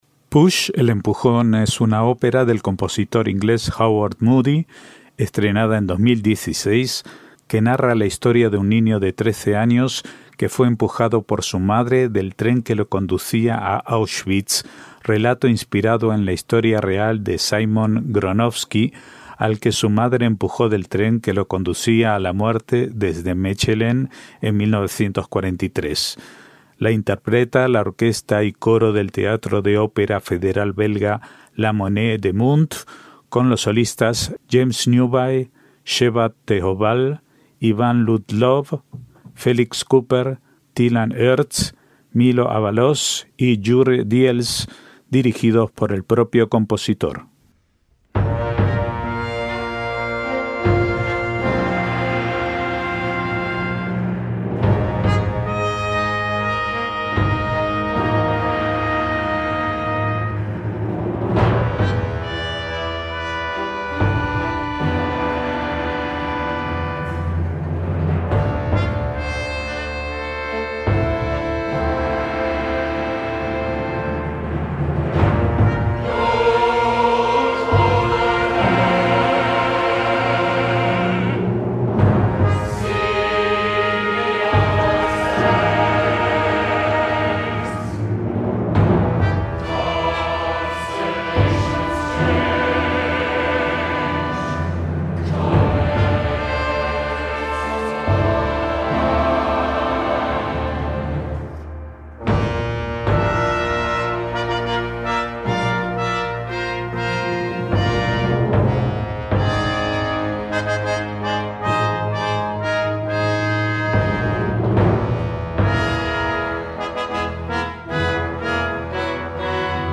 ÓPERA JUDAICA